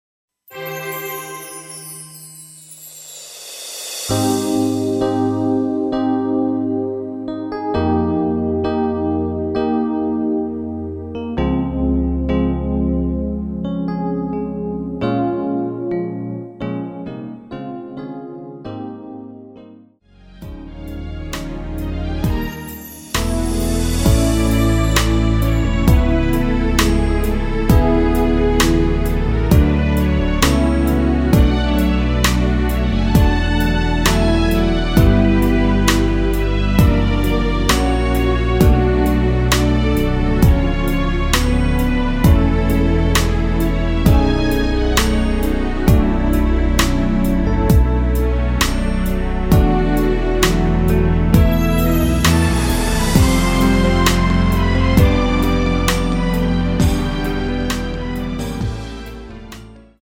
Ab
◈ 곡명 옆 (-1)은 반음 내림, (+1)은 반음 올림 입니다.
앞부분30초, 뒷부분30초씩 편집해서 올려 드리고 있습니다.
중간에 음이 끈어지고 다시 나오는 이유는